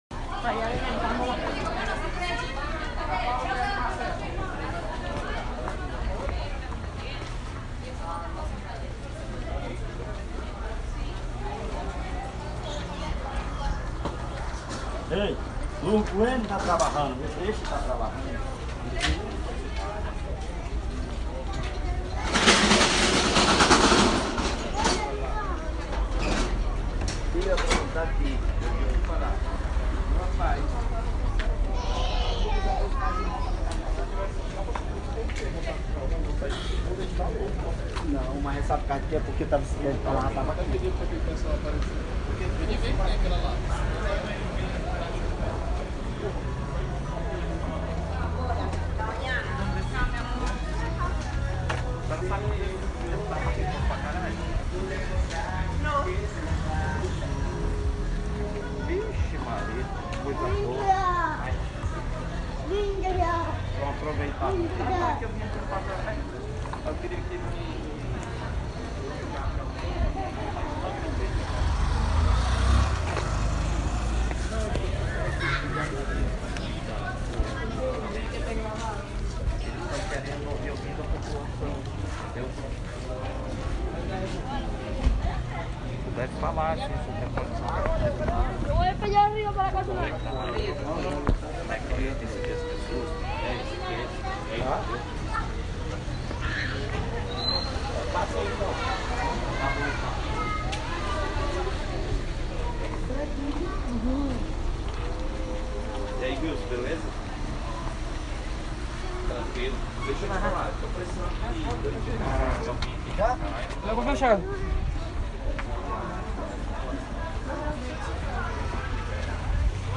Así suena la calle Suapí, la principal vía comercial de Pacaraima en la noche